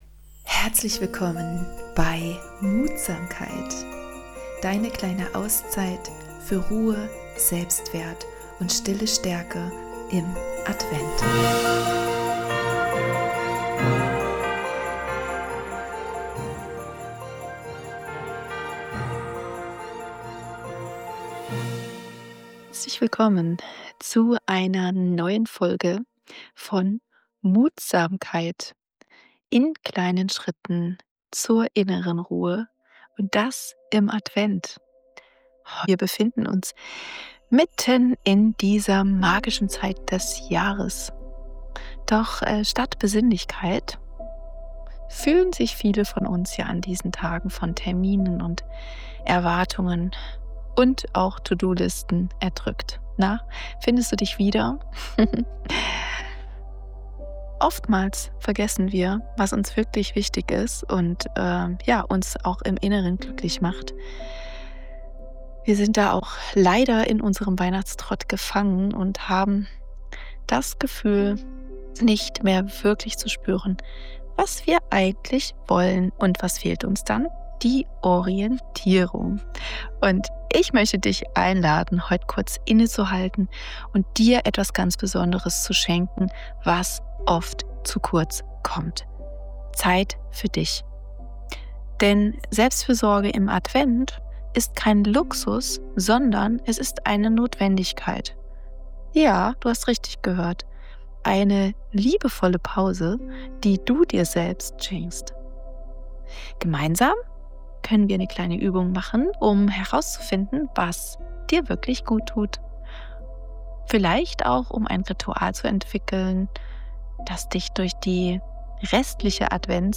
Darum widmet sich Türchen 11 einem Thema, das oft zu kurz kommt: innere Stärke durch Selbstfürsorge. In dieser Episode erwartet dich: eine achtsame Übung zur Orientierung: was brauche ich wirklich?